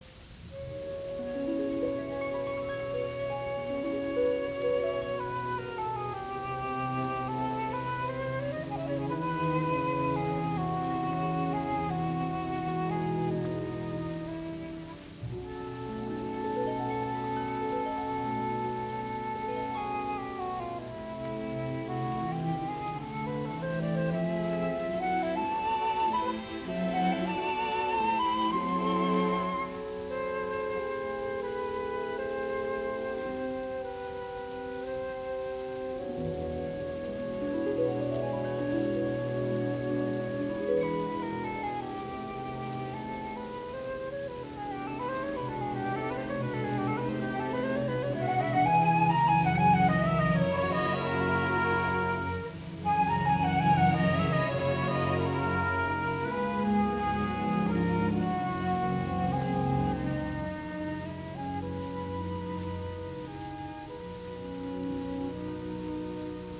當然浪漫主義式的演奏還是存在，樂段之間的連結還是非常自由到過於放縱。
只能說這個歷史錄音之轉錄實在太正確了！